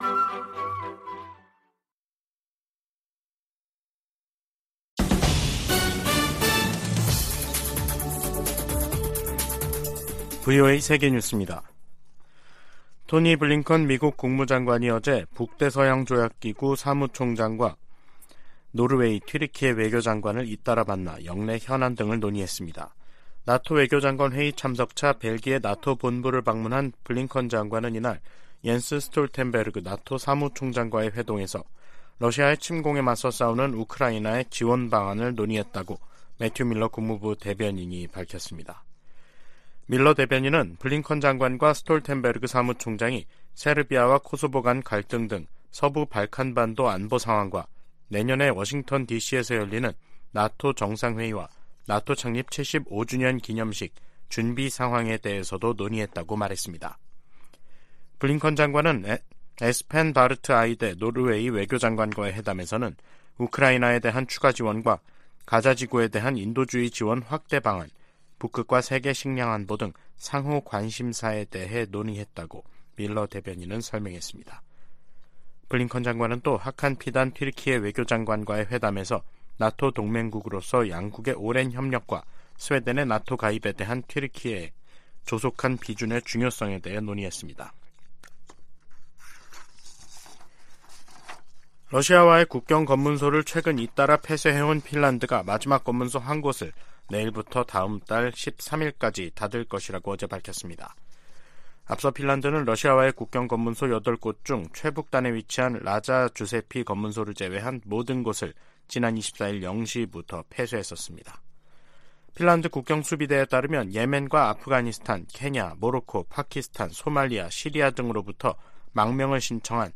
VOA 한국어 간판 뉴스 프로그램 '뉴스 투데이', 2023년 11월 29일 2부 방송입니다. 미국 정부는 북한의 정찰위성 발사를 규탄하면서 면밀히 평가하고, 러시아와의 협력 진전 상황도 주시하고 있다고 밝혔습니다. 미 국무부는 북한이 비무장지대(DMZ) 내 최전방 감시초소(GP)에 병력과 장비를 다시 투입한 데 대해 긴장을 부추기고 있다고 비판했습니다. 북한 정찰위성은 고화질 사진을 찍을 수 없다고 전문가들이 평가했습니다.